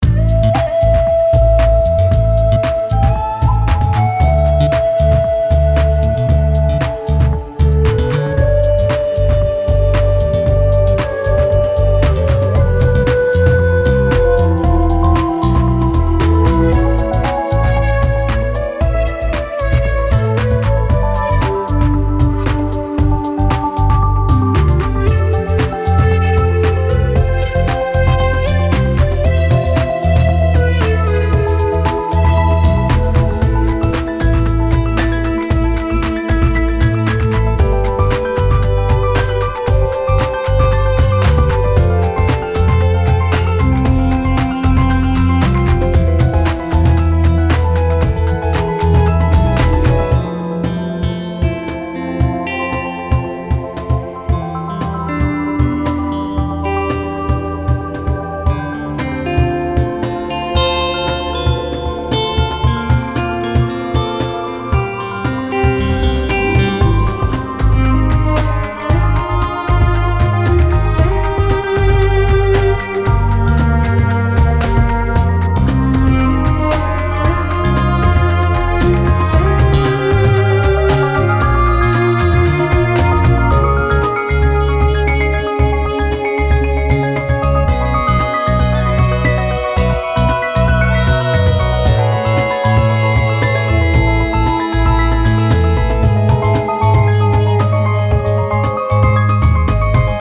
听到这充满异域风情的旋律，仿佛身处在另一个国度之中。